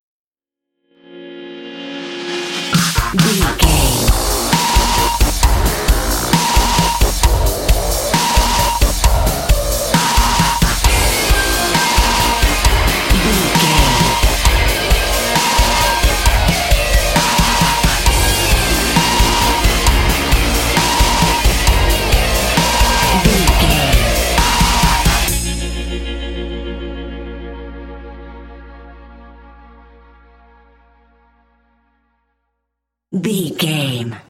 Aeolian/Minor
B♭
drums
synthesiser
electric guitar
nu metal